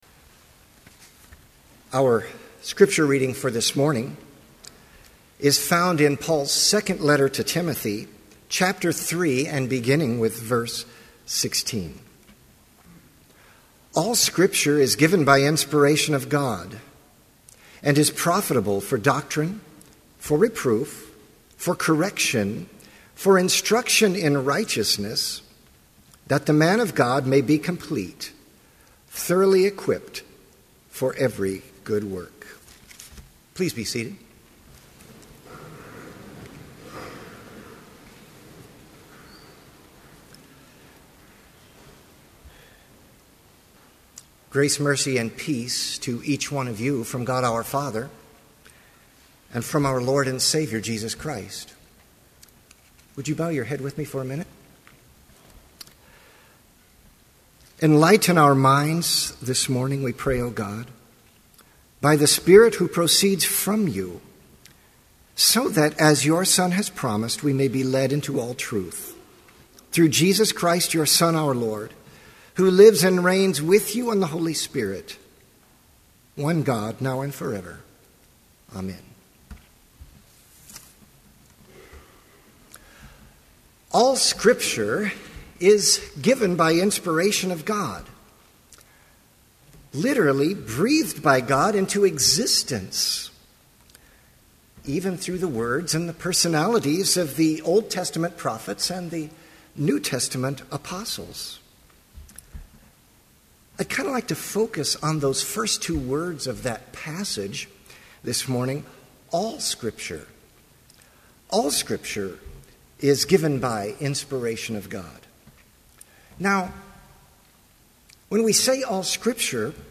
Chapel worship service held on February 17, 2012, BLC Trinity Chapel, Mankato, Minnesota, (audio available)
Complete service audio for Chapel - February 17, 2012